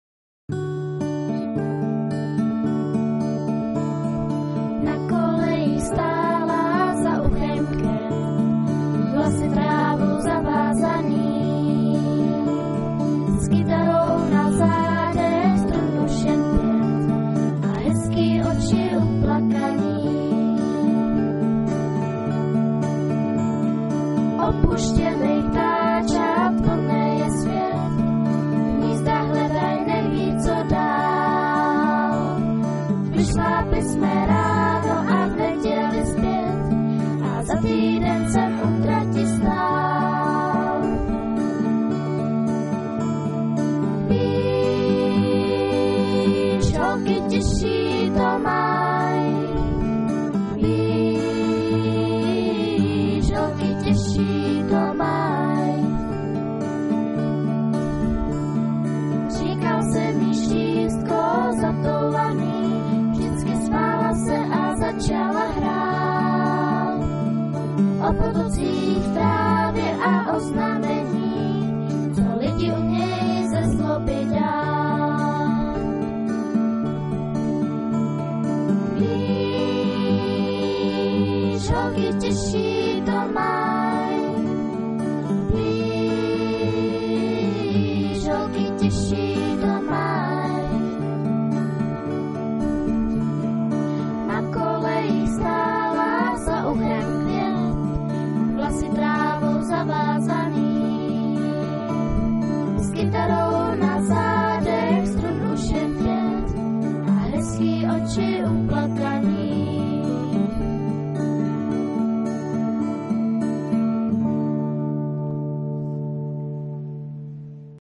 Hudební studio mladých Český Krumlov
TÁBOR/SEZIMOVO ÚSTÍ - V loňském roce na táborské Bambiriádě 2006 opět vystoupili vítězové Dětské porty z Českého Krumlova - kapela Popelki z Malonty